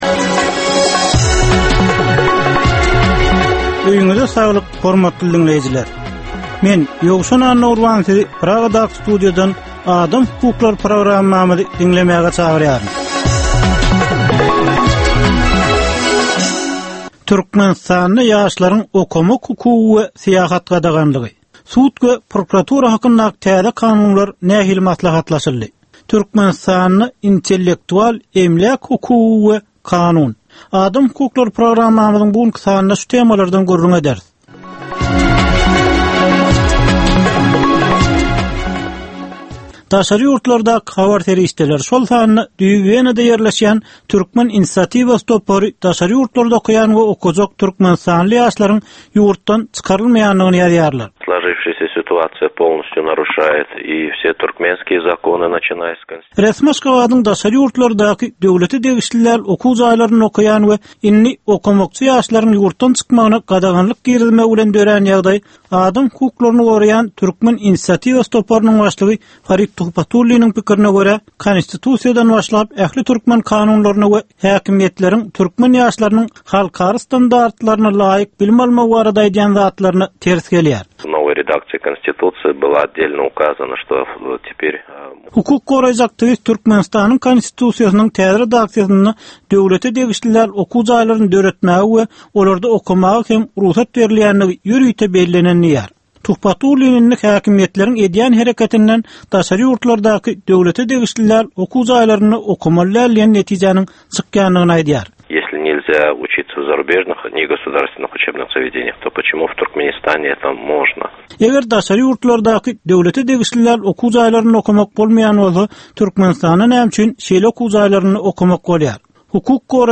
Türkmenistandaky ynsan hukuklarynyn meseleleri barada 15 minutlyk ýörite programma. Bu programmada ynsan hukuklary bilen baglanysykly anyk meselelere, problemalara, hadysalara we wakalara syn berilýar, söhbetdeslikler we diskussiýalar gurnalýar.